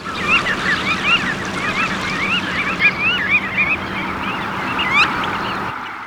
Calidris canutus
VOZ: Usualmente no vocaliza en la migración excepto por unas notas de contacto tenues.